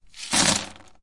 冰 " 水冰冷却器将一把冰块丢进冷却器 02
描述：将少量冰块放入冷却器中。 用Tascam DR40录制。